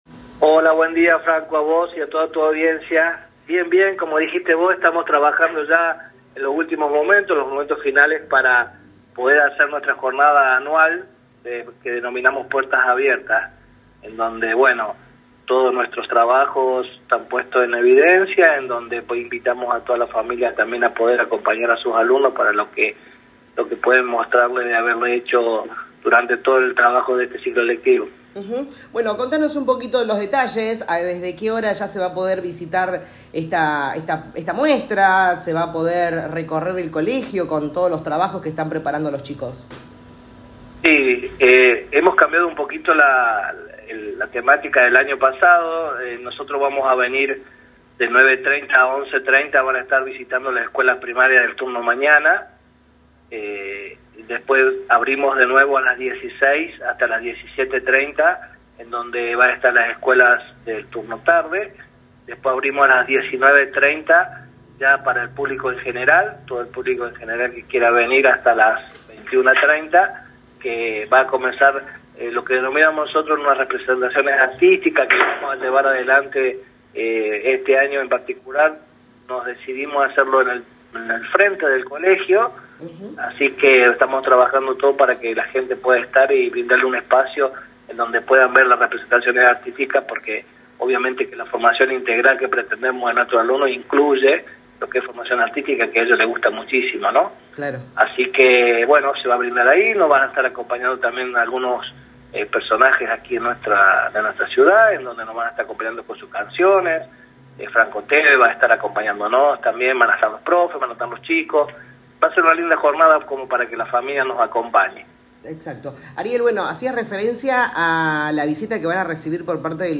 En diálogo con LA RADIO 102.9